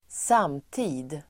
Ladda ner uttalet
samtid substantiv, contemporary period , our [own] time (age) Uttal: [²s'am:ti:d] Böjningar: samtiden Definition: en persons egen tid, nutid Exempel: han blev inte uppskattad av sin samtid (he was not appreciated by his contemporaries)